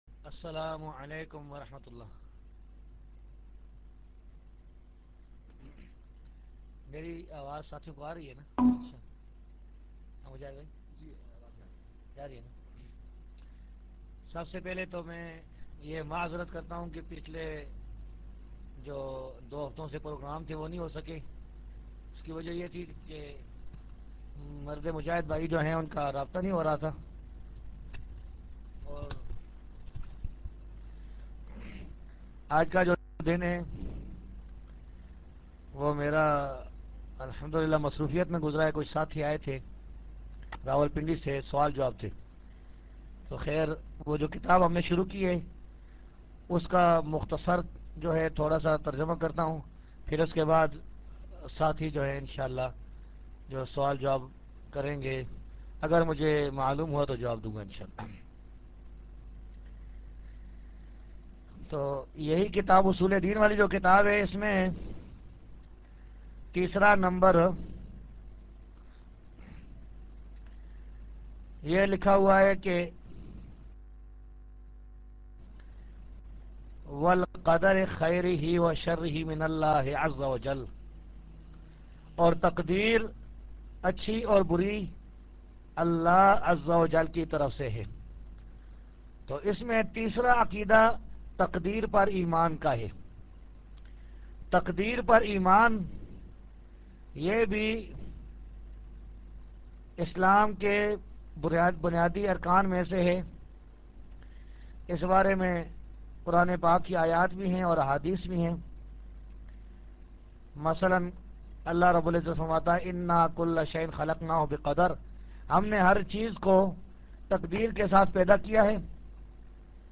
Dars-e-Hadeeth Aur Sawaal Jawaab